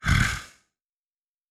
Monster_03_Attack.wav